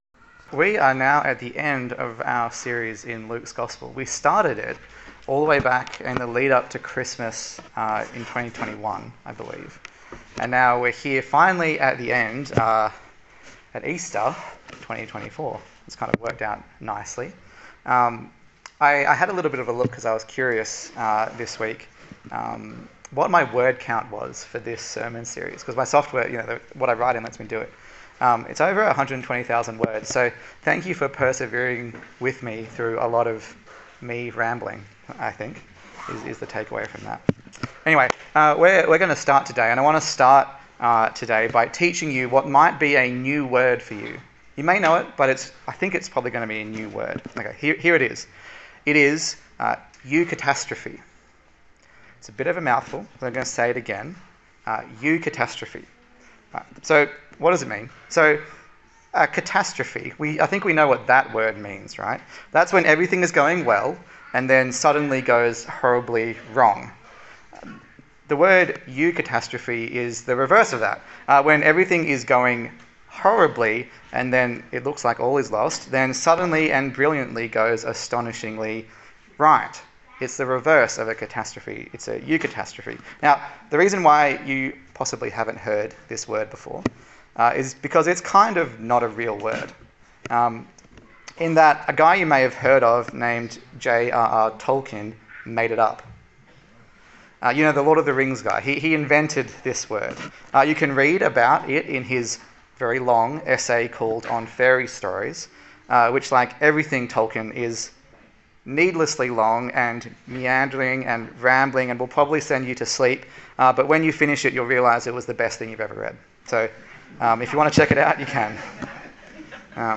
Luke Passage: Luke 24 Service Type: Easter Sunday